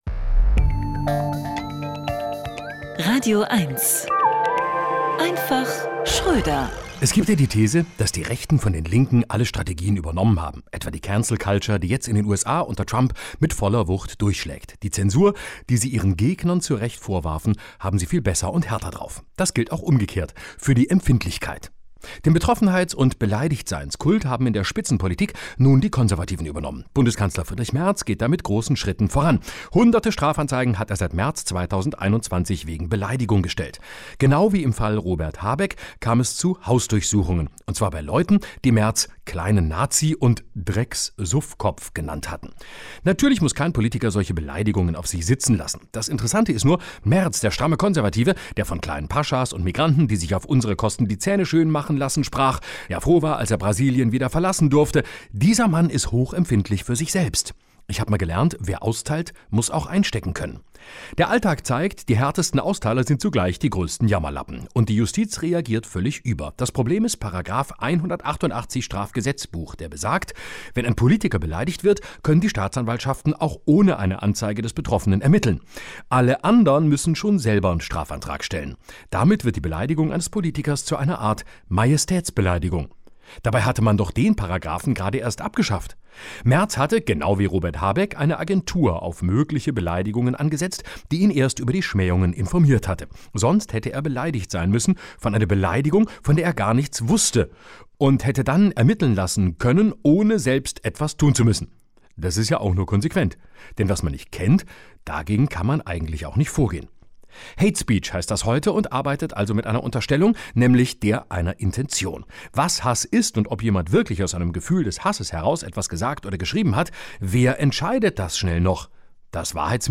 Genres: Comedy